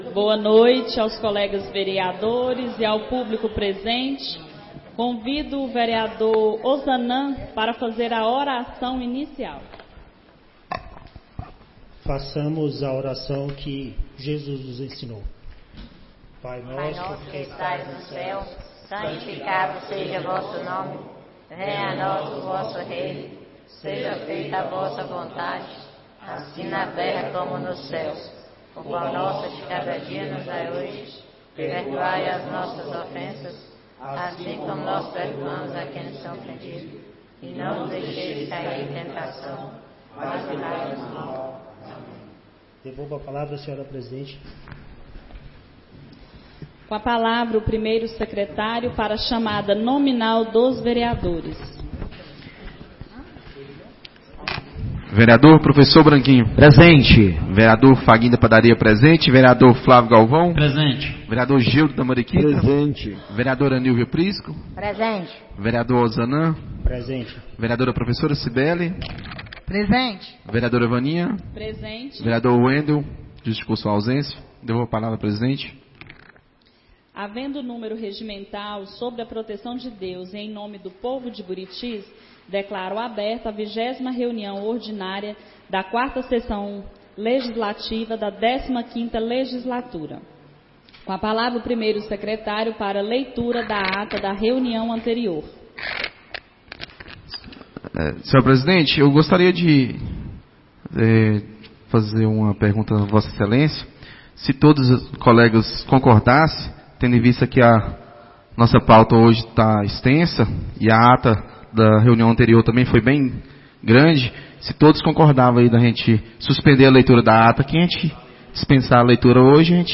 20ª Reunião Ordinária da 4ª Sessão Legislativa da 15ª Legislatura - 17-06-24